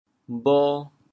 潮州 gao2 bho2 he5 潮阳 gao2 bho2 hu5 潮州 0 1 2 潮阳 0 1 2